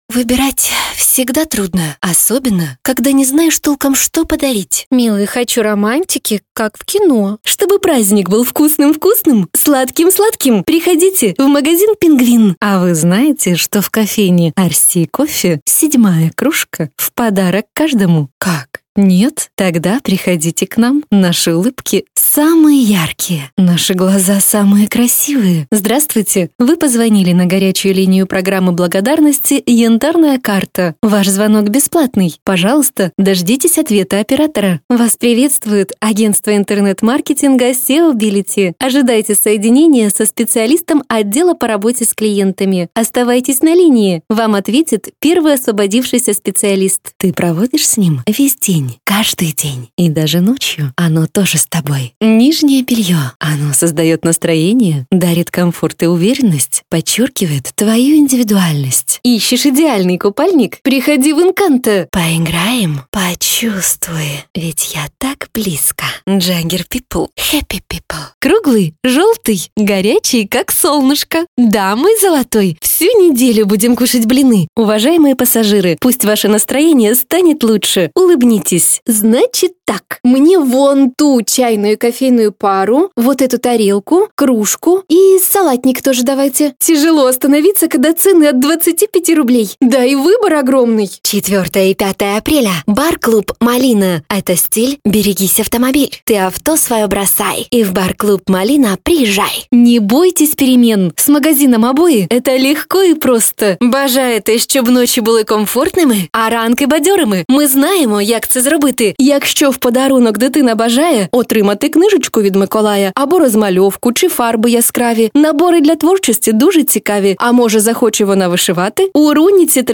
Женский дикторский голос
Читает свободно, русский, украинский.